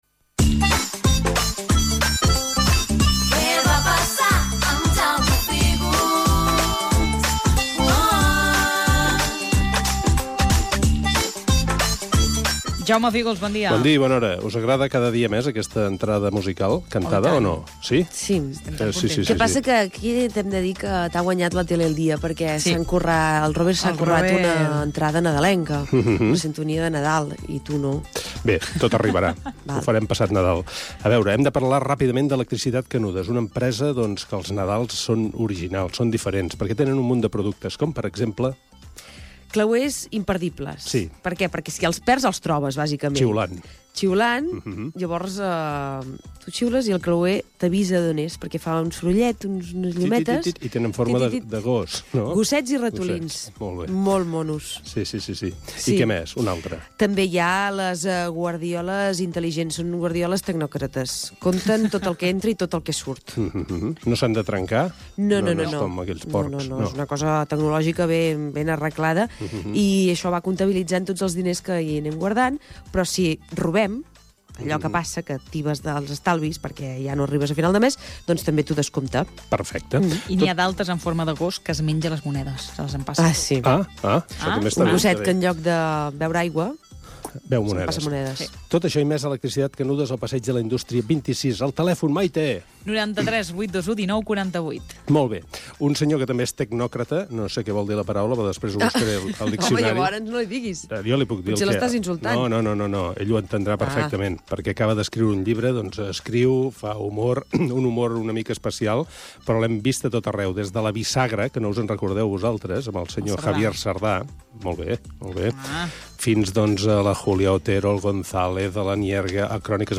Espai "Què va passar?". Careta del programa, publicitat i entrevista telefònica a Juan Carlos Ortega
Entreteniment